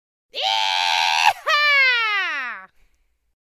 Звуки ковбоев
Ковбойский клич «ииихааа»